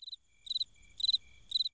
UN GRILLO
Tonos EFECTO DE SONIDO DE AMBIENTE de UN GRILLO
Un_grillo.mp3